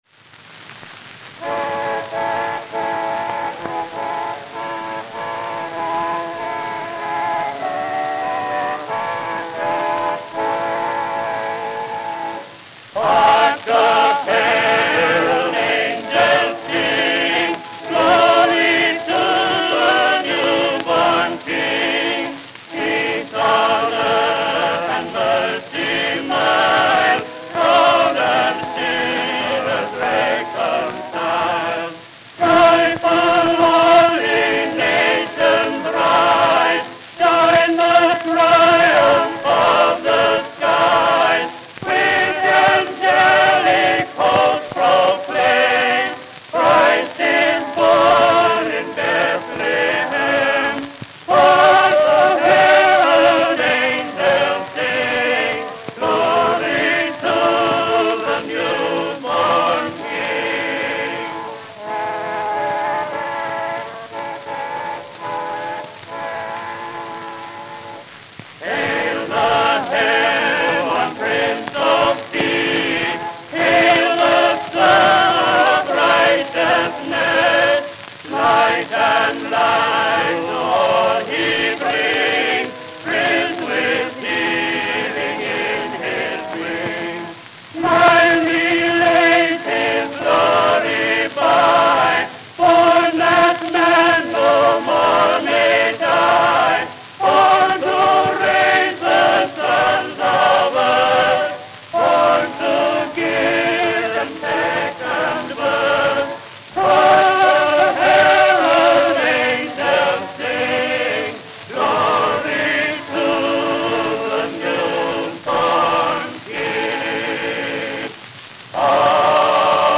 Shrinkage remained a problem and can make Indestructibles hard to play today without skipping.
Male Quartette